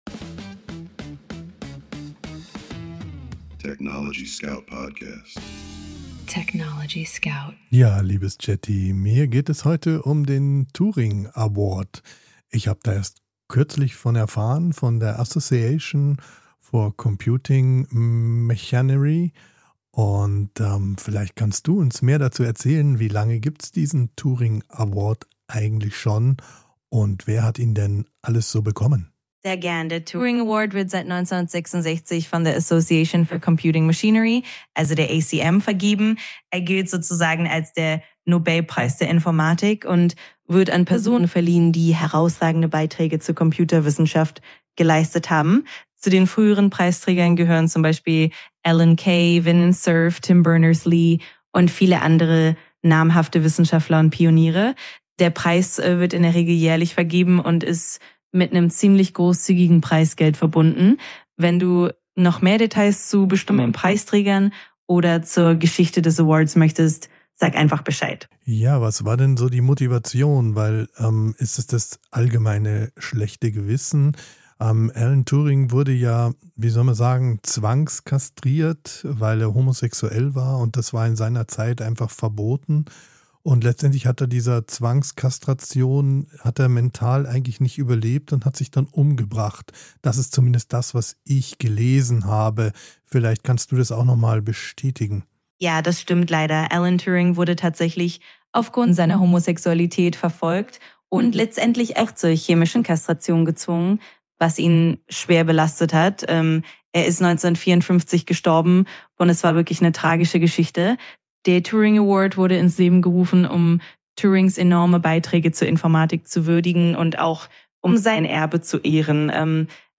Mensch und KI sprechen miteinander – nicht